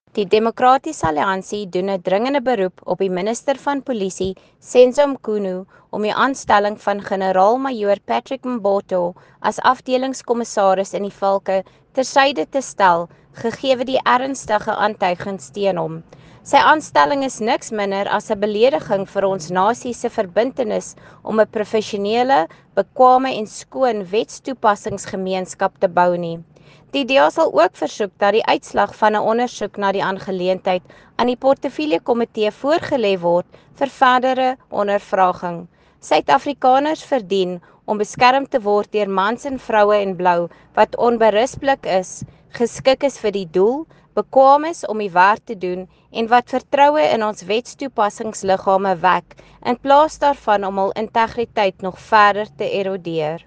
Note to editors: Please find attached soundbites in English and
Afrikaans by Lisa Schickerling MP